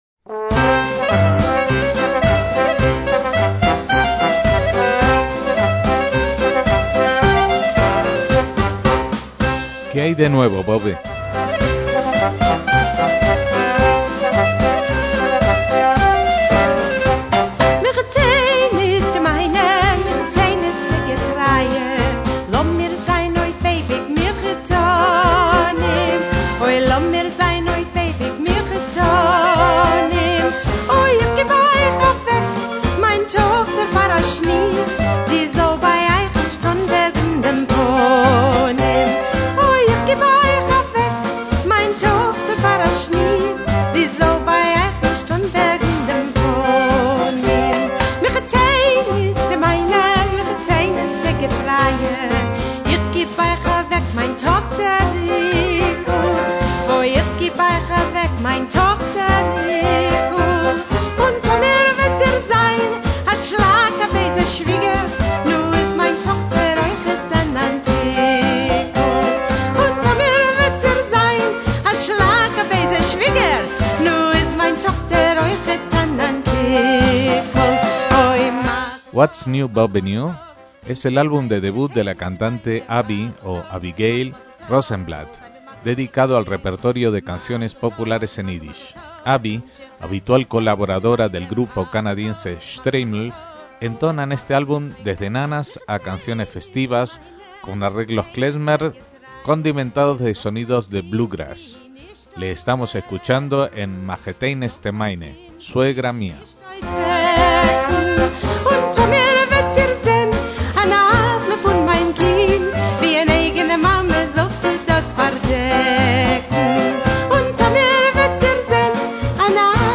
MÚSICA ÍDISH